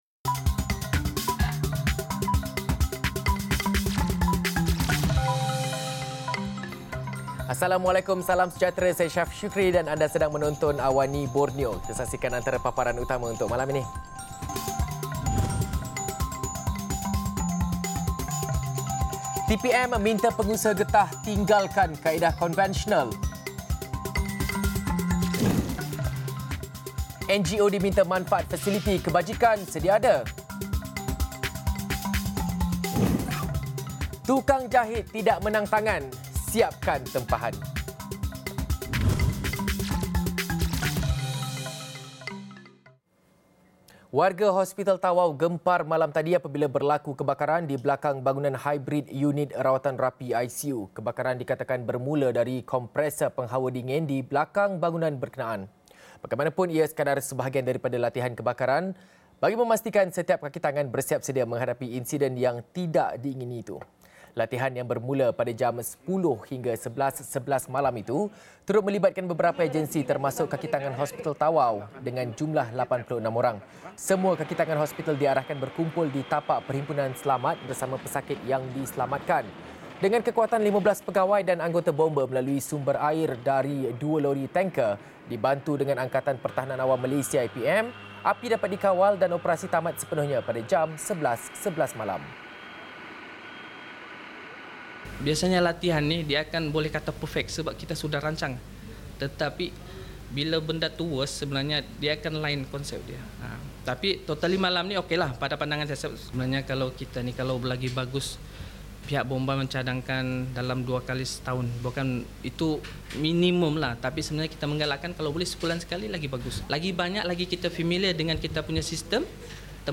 Laporan berita padat dan ringkas dari Borneo